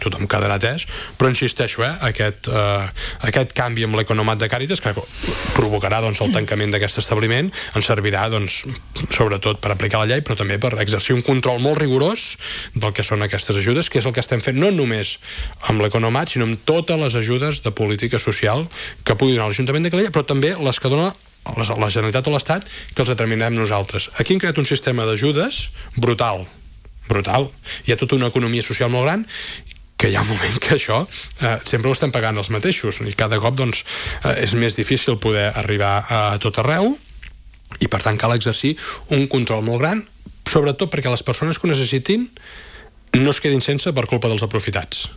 N’ha parlat en una entrevista al matinal de Ràdio Calella Televisió, on ha detallat que el nou sistema s’implantarà a partir del 2026.